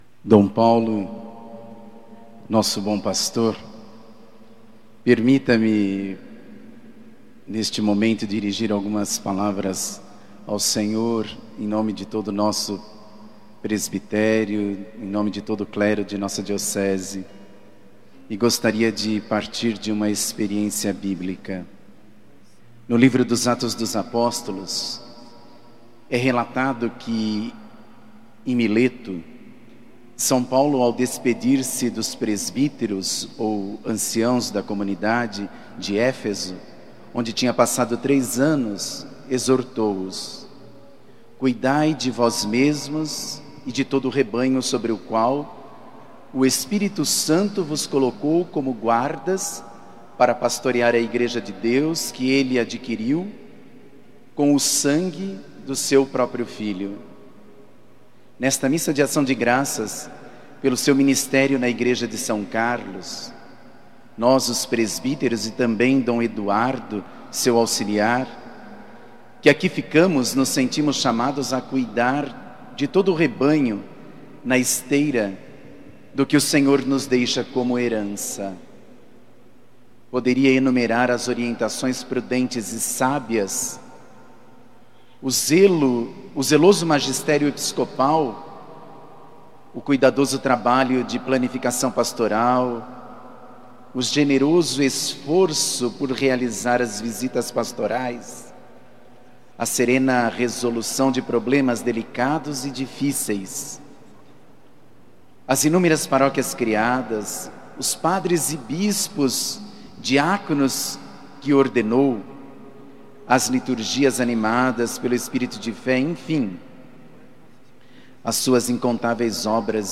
Uma missa na Catedral de São Carlos, neste sábado (05) marcou a ação de graças pelo ministério episcopal de Dom Paulo Cezar Costa como 7º Bispo Diocesano.